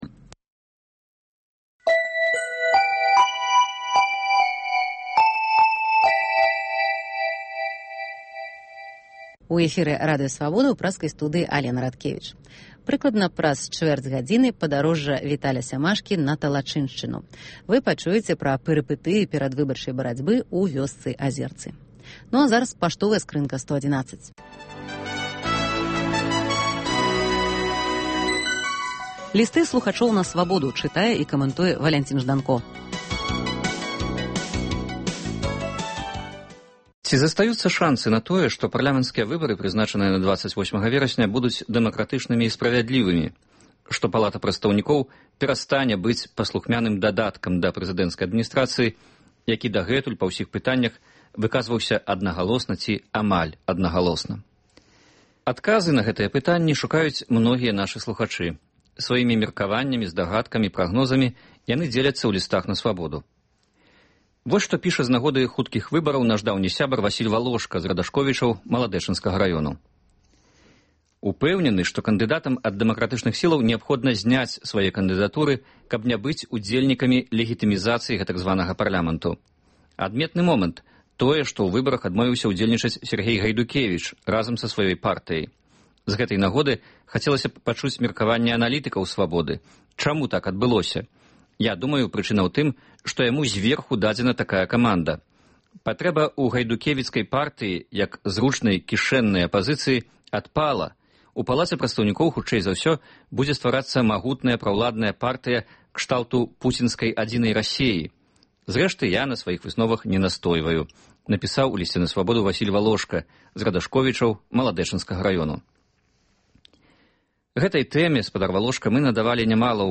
Гутарка экспэртаў за круглым сталом